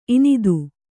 ♪ inidu